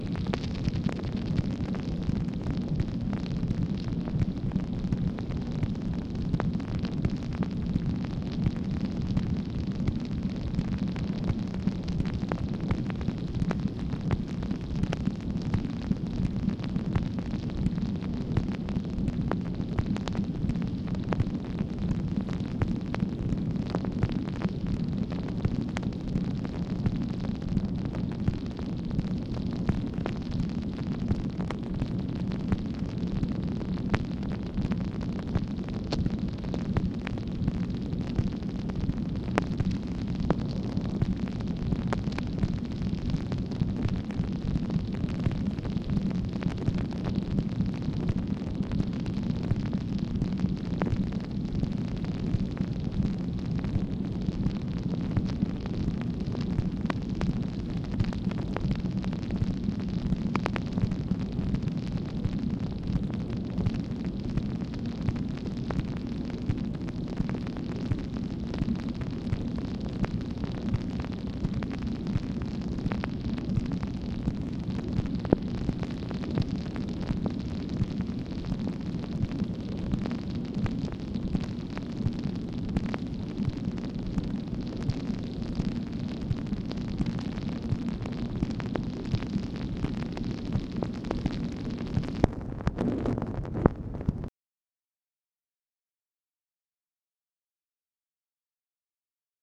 MACHINE NOISE, June 10, 1965